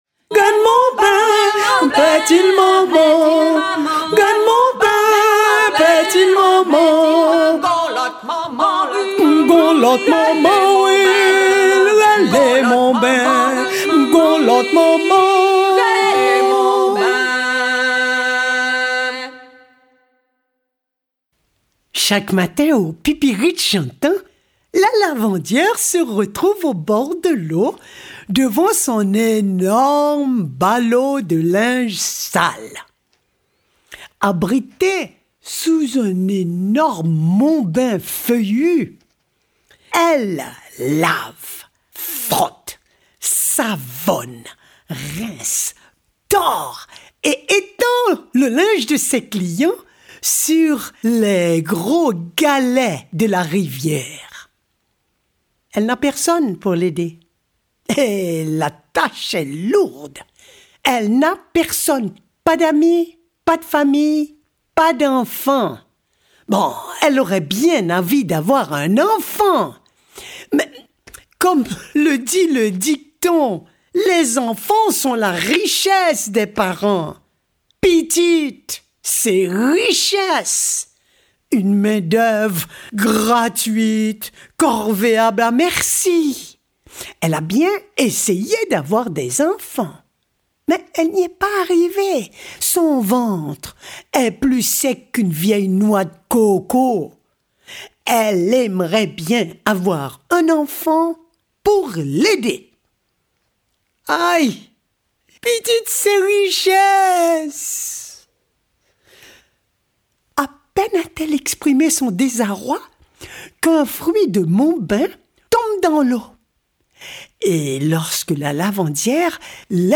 Lire un extrait Mimi Barthélémy Ma Beauté affronte le Diable Oui'Dire Editions Collection : Contes d’auteurs Date de publication : 2016-05-31 Le conte chanté, hérité de l’Afrique, encore vivant en Haïti et dans les Antilles, est un genre dans lequel conte et chant servent au même titre le récit. Dans ces contes, Mimi Barthélémy, la plus illustre représentante de cette tradition orale originale, chante en créole et conte en français, dans son français.